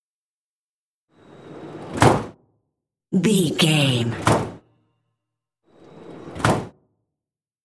Van cargo lateral door close slide
Sound Effects